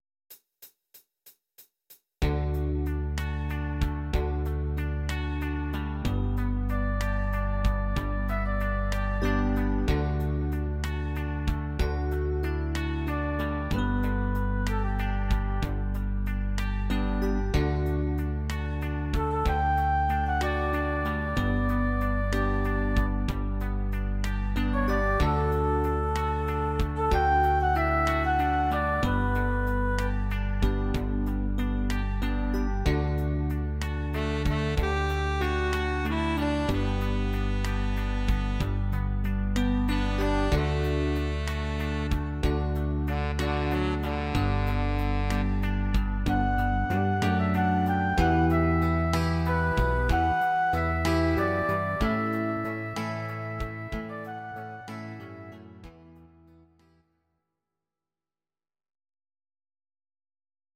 Audio Recordings based on Midi-files
Pop, 2010s